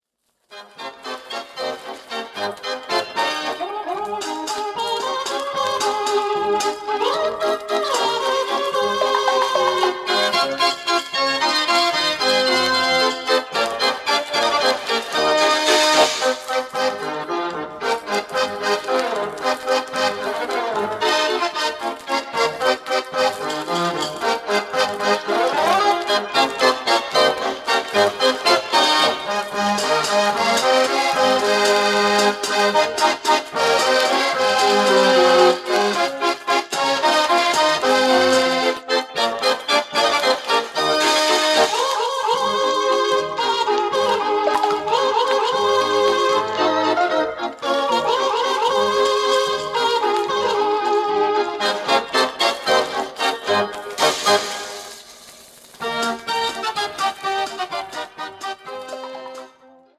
dansstukken, waaronder fox, wals, polka en one-step.
Formaat 78 toerenplaat, schellak
krachtige en rijke